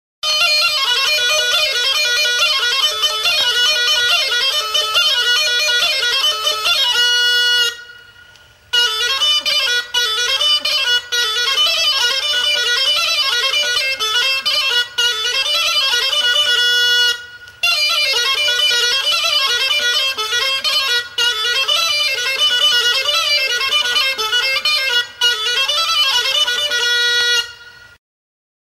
Aerophones -> Reeds -> Single fixed (clarinet)
SOLO DE XEREMIA.
EUROPE -> BALEARRAK
Klarinete sinplea da.